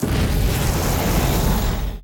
Free Fantasy SFX Pack
SFX / Spells / Firespray 1.wav
Firespray 1.wav